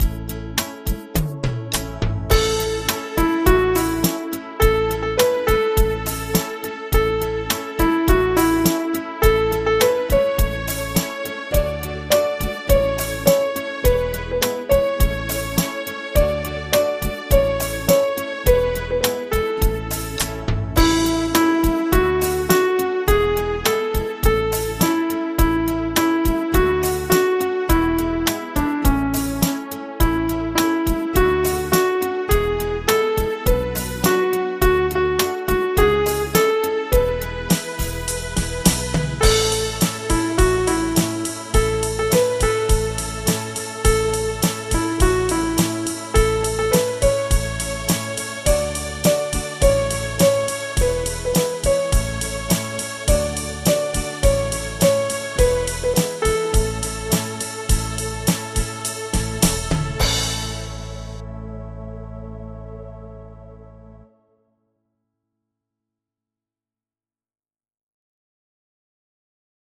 Audio Midi Bè Nữ: download